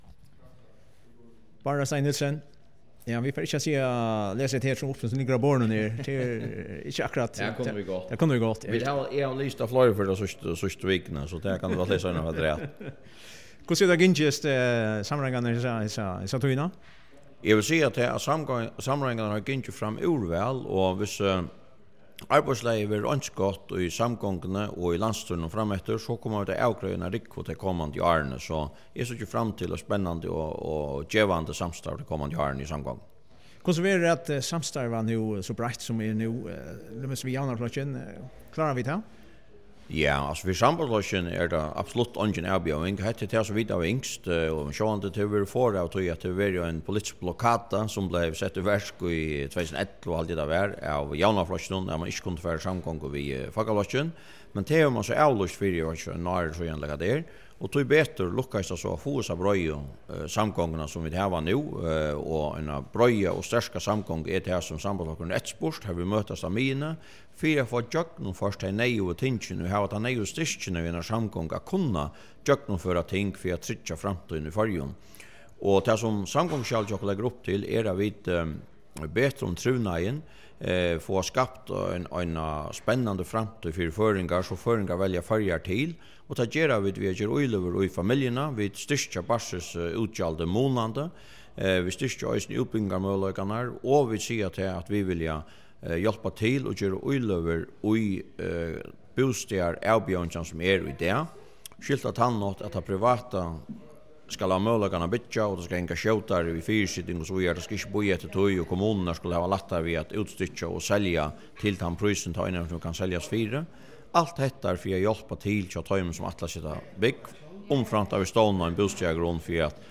Hoyr formannin í Sambandsflokkinum, Bárður á Steig Nielsen, sum eisini verður landsstýrismaður, greiða frá gongdini í samráðingunum.